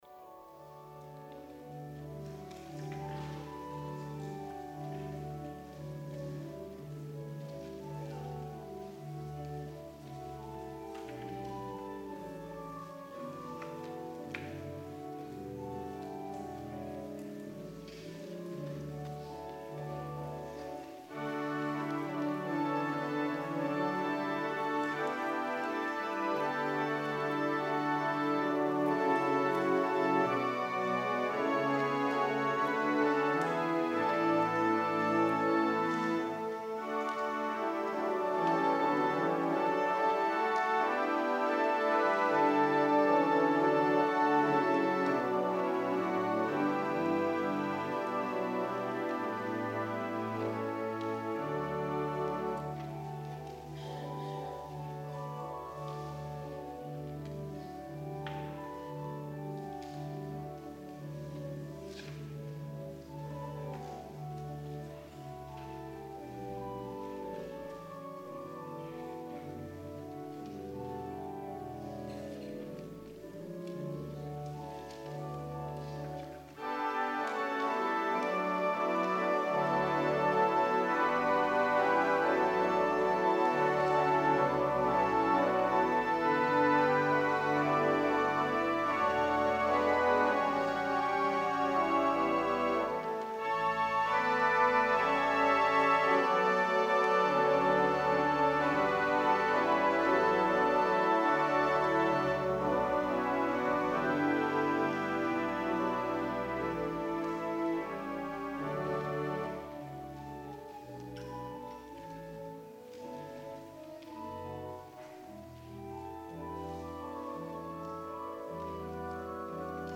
VOLUNTARY Blessed Assurance
organ